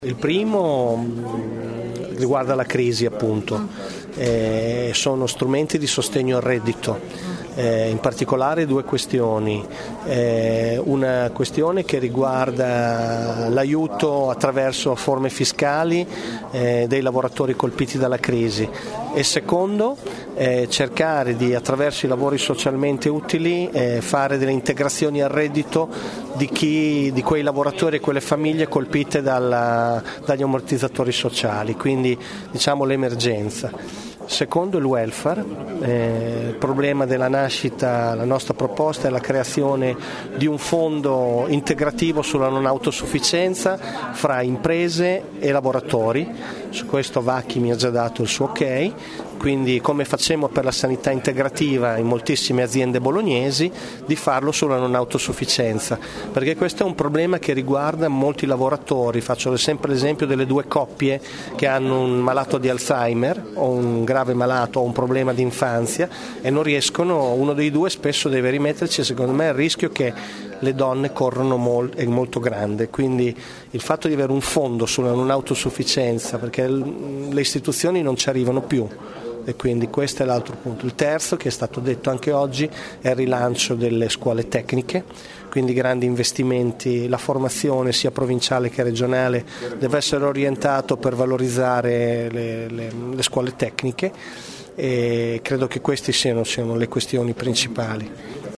A margine del forum, racconta le proposte del sindacato per superare la crisi, rilanciare l’economia bolognese e sostenere il welfare.